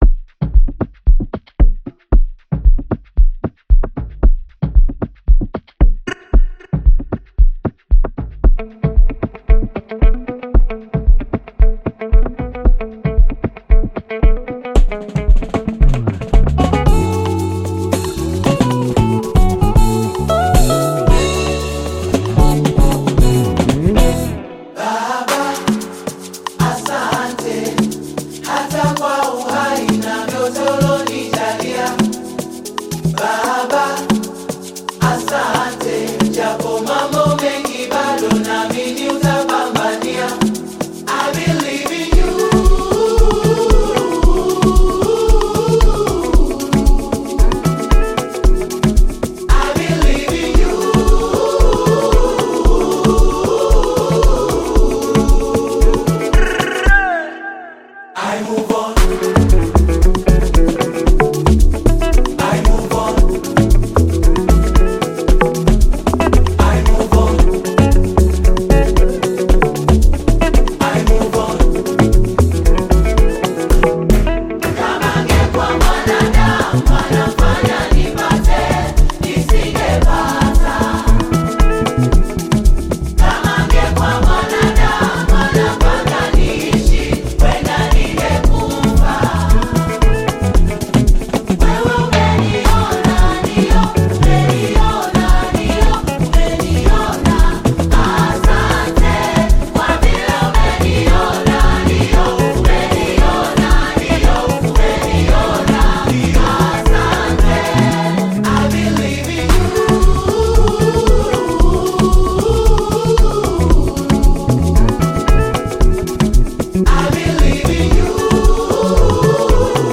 Tanzanian Bongo Flava Choir and Band
Bongo Flava song